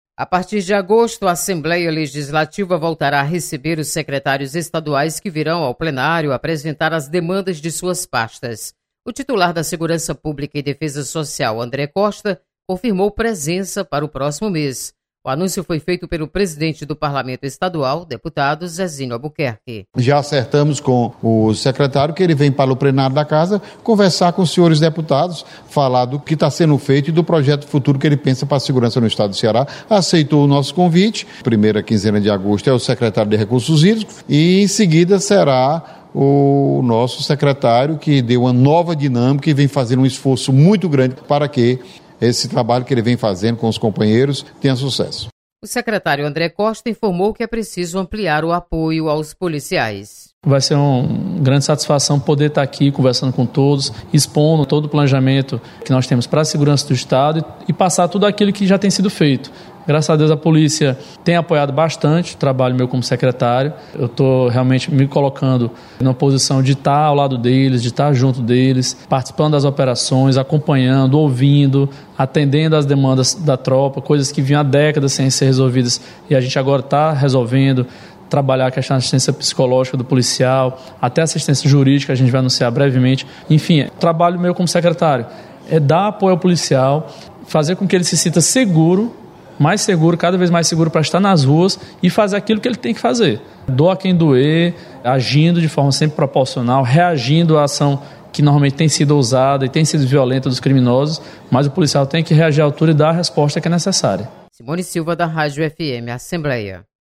Visitas de Secretários de estado ao Parlamento serão retomadas em Agosto. Repórter